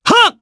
Clause-Vox_Attack3_jp.wav